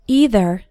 Both either and neither have two possible pronunciations:
Pronunciation #2 – EITHER